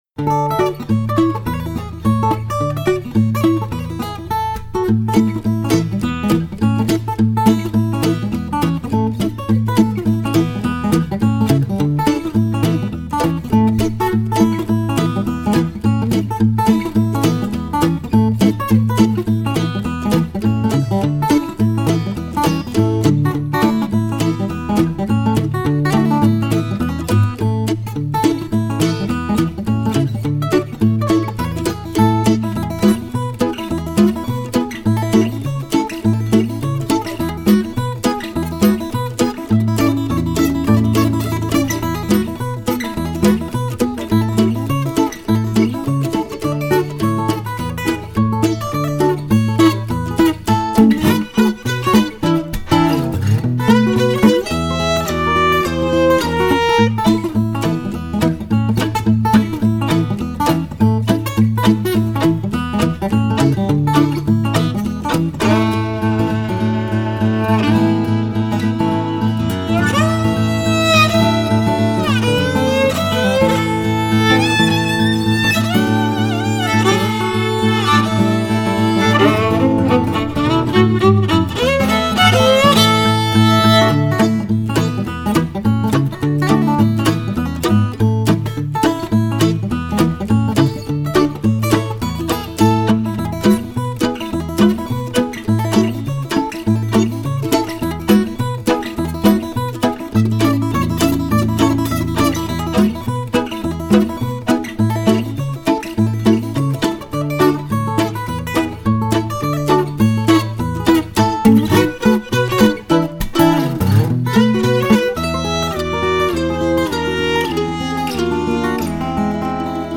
Ragtime aux couleurs cajun, à écouter le matin!
harmonica
violon, mandoline
guitares
clavier
guitare, ukulele